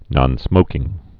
(nŏnsmōkĭng)